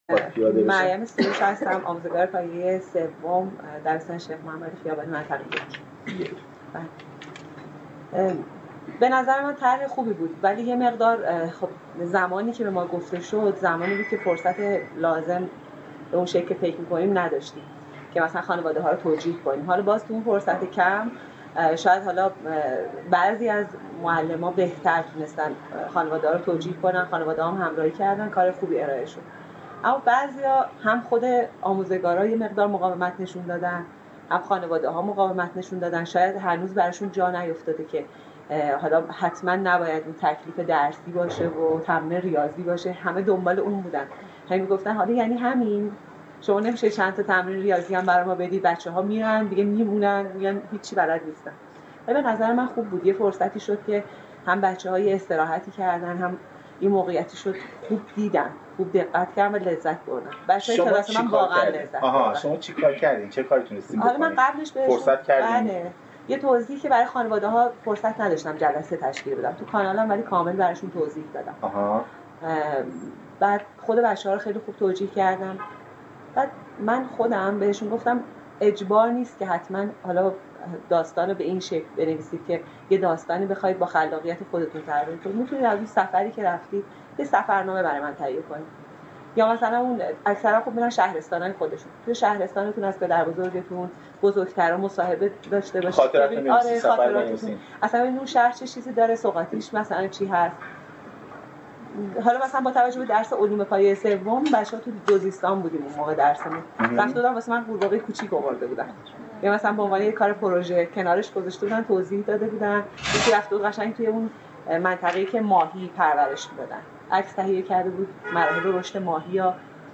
فایل صوتی میزگرد معلمان دوره آموزش ابتدایی